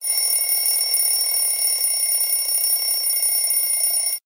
alarm_clock-online-audio-converter.mp3